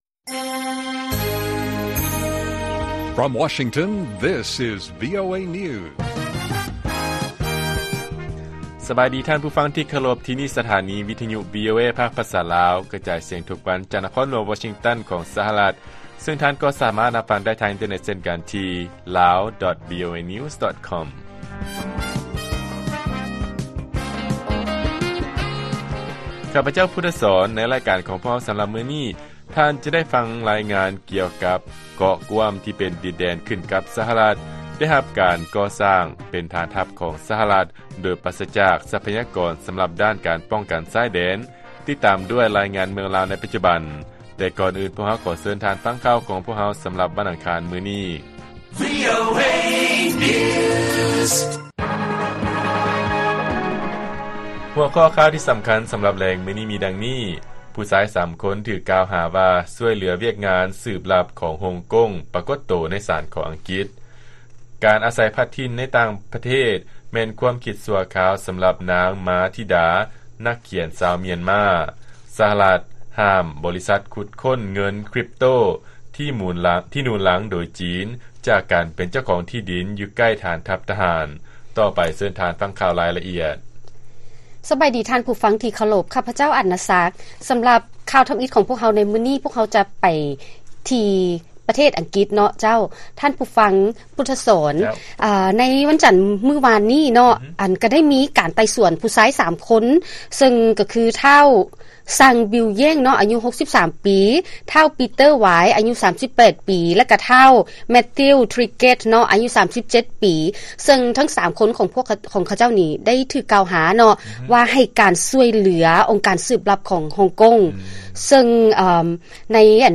ລາຍການກະຈາຍສຽງຂອງວີໂອເອລາວ: ຜູ້ຊາຍ 3 ຄົນ ທີ່ຖືກກ່າວຫາວ່າ ຊ່ວຍເຫຼືອວຽກງານສືບລັບຂອງຮົງກົງ ປາກົດໂຕ ໃນສານຂອງອັງກິດ